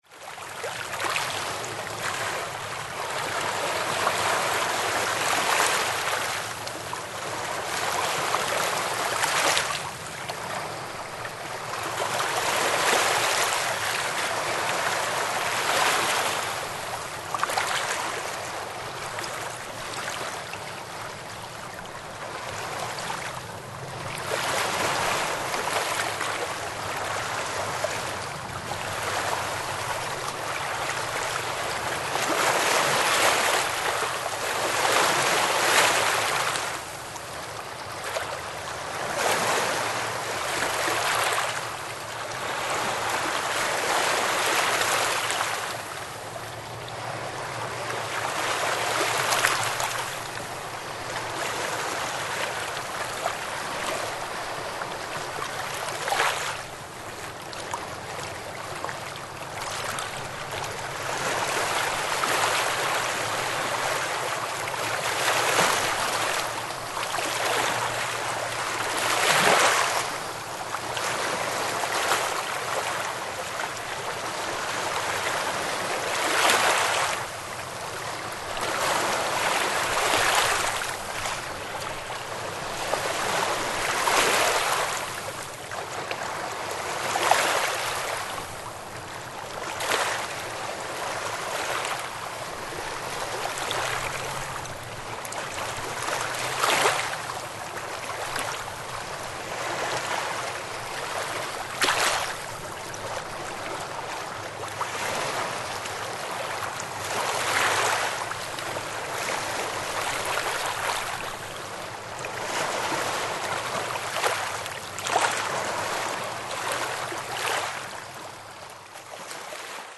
Шум реки: Плеск волн на воде